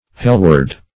hellward - definition of hellward - synonyms, pronunciation, spelling from Free Dictionary Search Result for " hellward" : The Collaborative International Dictionary of English v.0.48: Hellward \Hell"ward\, adv.